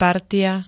partia [vysl. -tya], -ie, ií, -iám, -iách ž.
Zvukové nahrávky niektorých slov